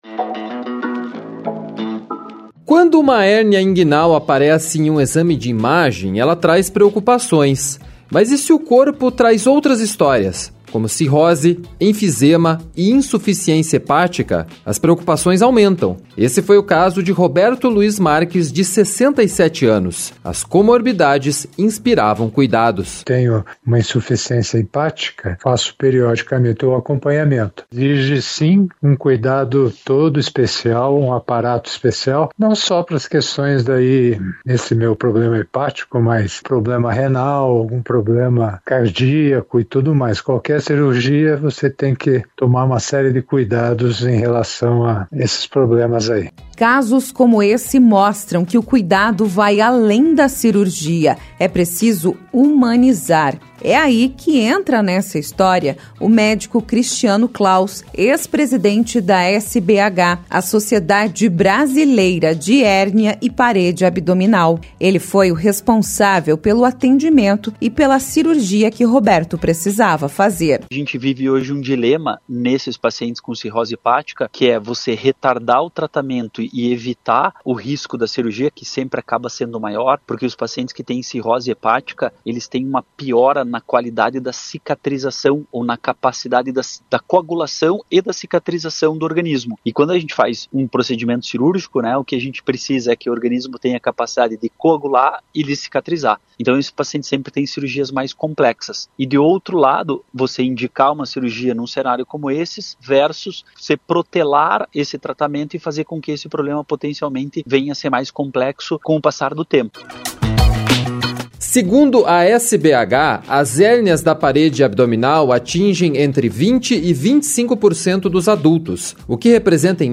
Reportagem 1 – Humanização dos casos: o cuidado além da cirurgia